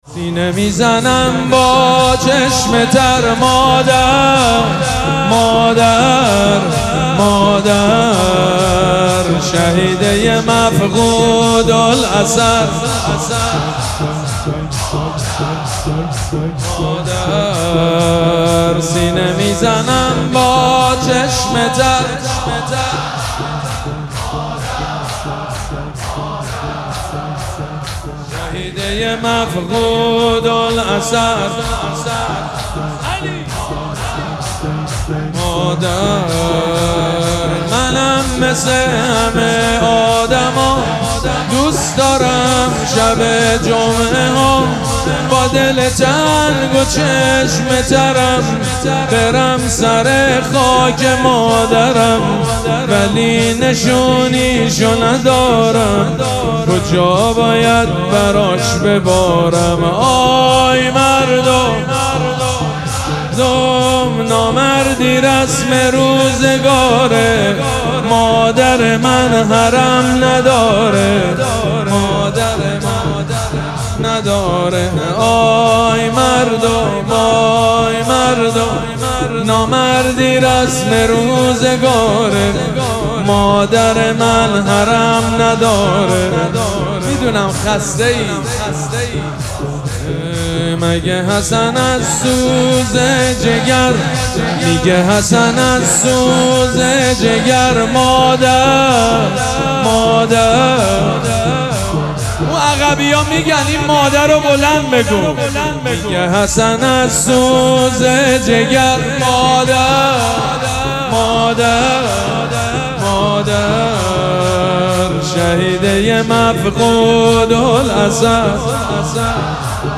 شور شب چهارم فاطمیه 1403